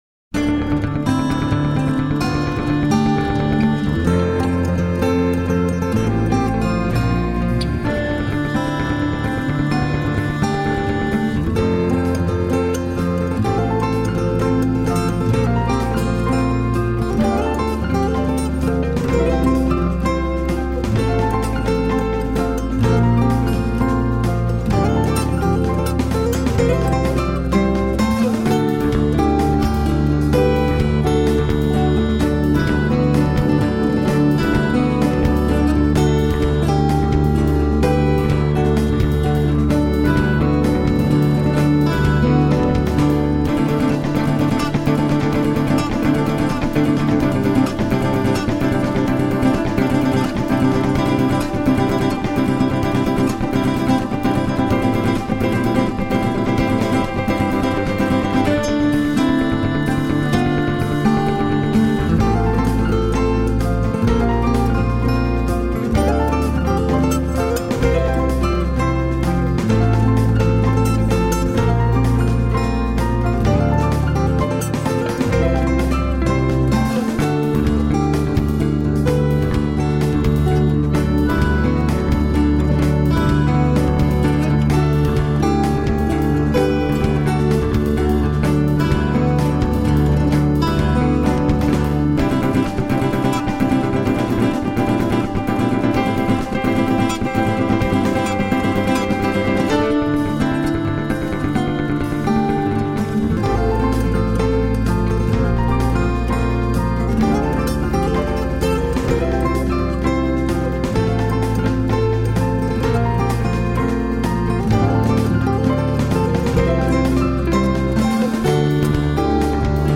A secret garden of lucid musical dreams .
Tagged as: New Age, Instrumental New Age, Contemporary Piano